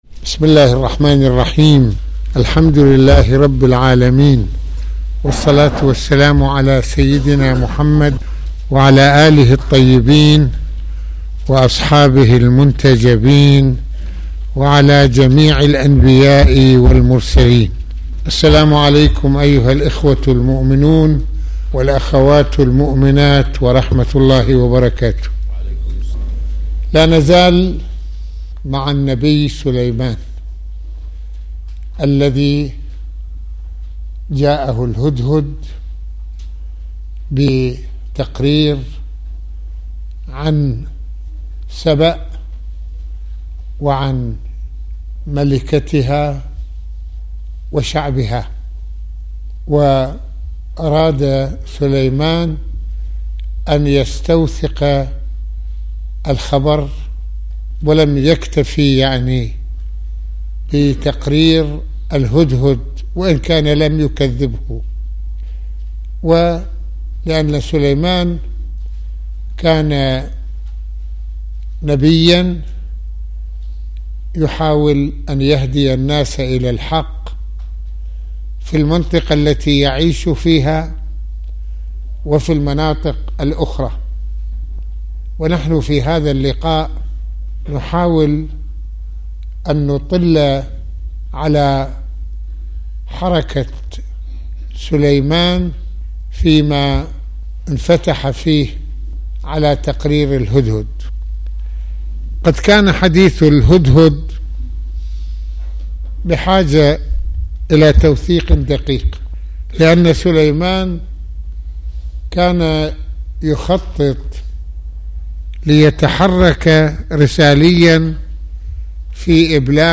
- المناسبة : خطبة الجمعة المكان : مسجد الإمامين الحسنين (ع) المدة : 37د | 49ث المواضيع : النبي سليمان والتحقيق فيما قاله الهدهد عن مملكة سبأ - كيف انفتح النبي سليمان على تقرير الهدهد ؟